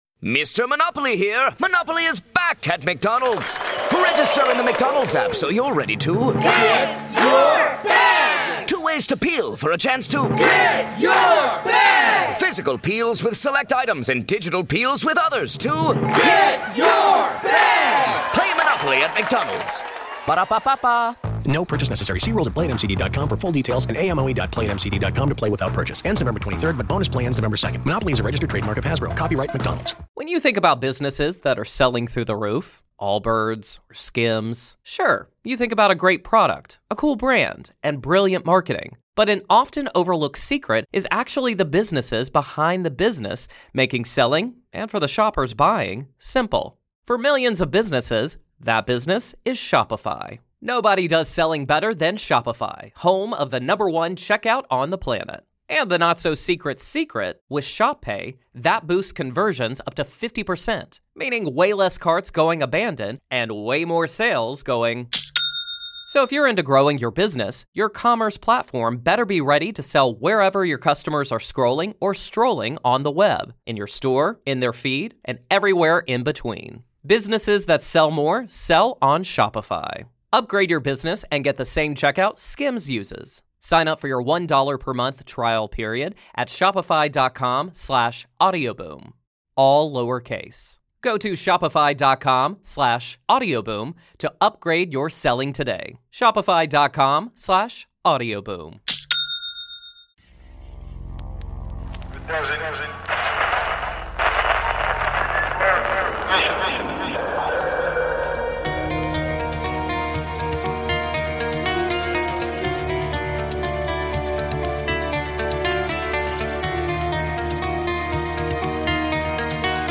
Witnesses of bigfoot, sasquatch, UFOs, aliens, ghosts and an array of other creatures from the paranormal and cryptozoology realm detail their encounters.